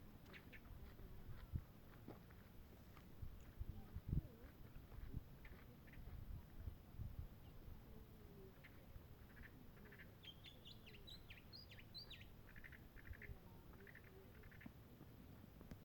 Tordo Patagónico (Curaeus curaeus)
Nombre en inglés: Austral Blackbird
Localidad o área protegida: Parque Natural Aguas de Ramón
Condición: Silvestre
Certeza: Observada, Vocalización Grabada